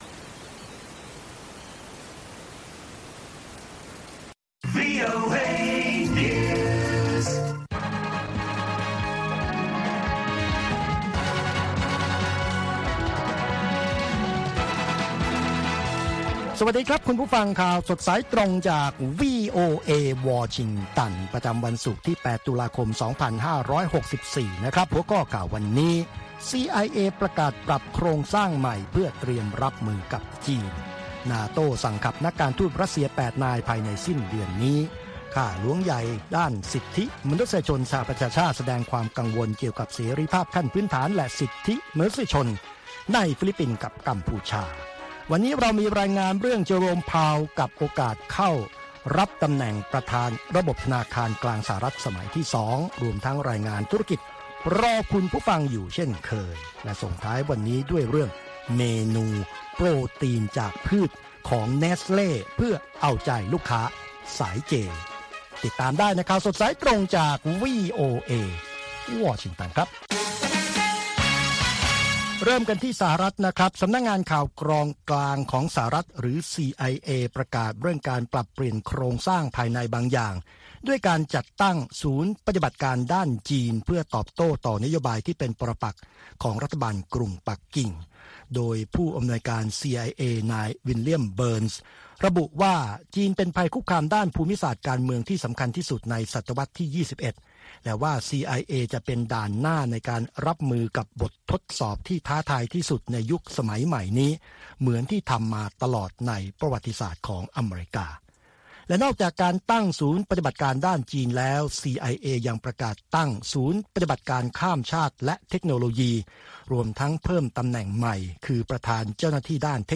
ข่าวสดสายตรงจากวีโอเอ ภาคภาษาไทย ประจำวันศุกร์ที่ 8 ตุลาคม 2564 ตามเวลาประเทศไทย